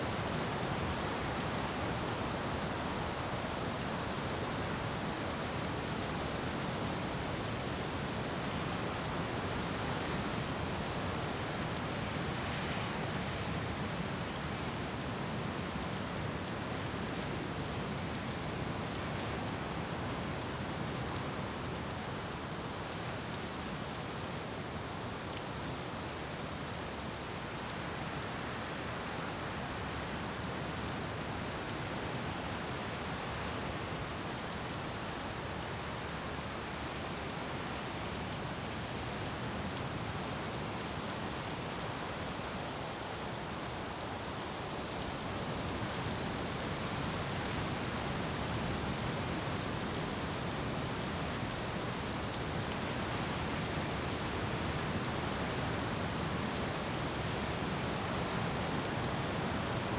Sea Rain.ogg